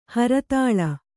♪ haratāḷa